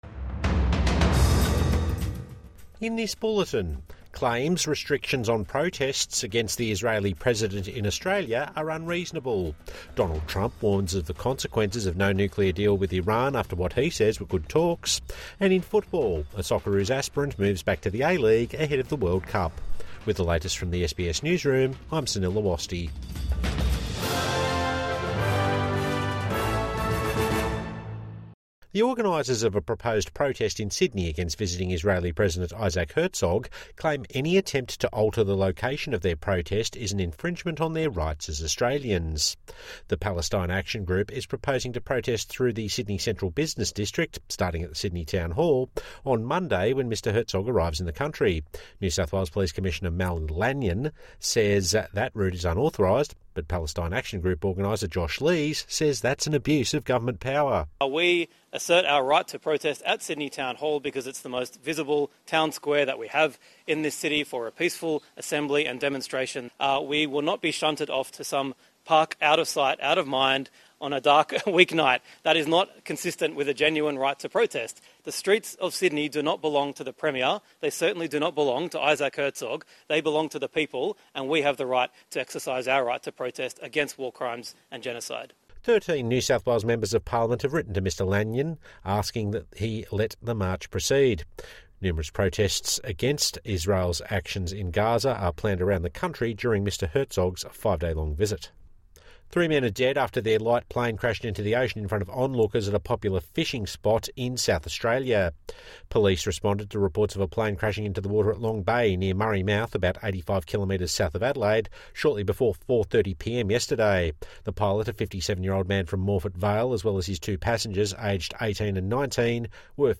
Protesters say restrictions on Sydney anti-Herzog march are unreasonable | Evening News Bulletin 7 February 2026